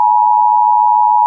EAS_beep.wav